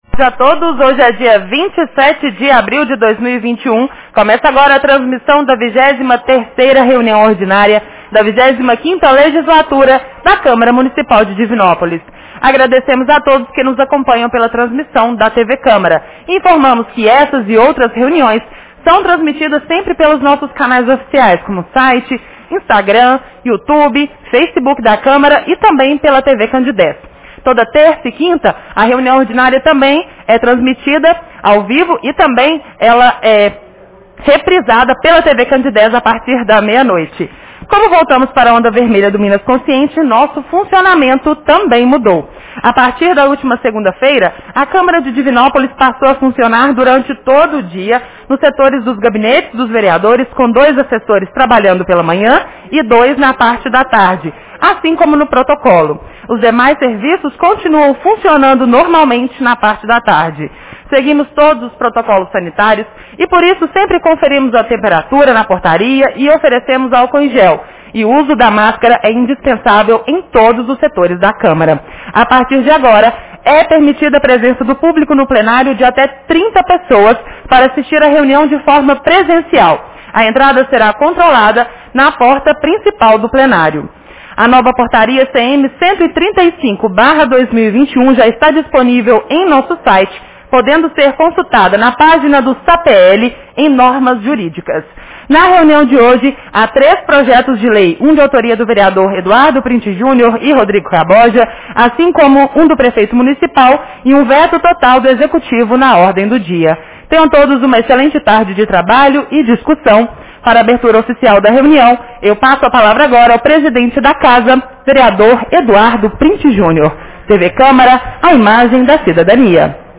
Reunião Ordinária 23 de 27 de abril 2021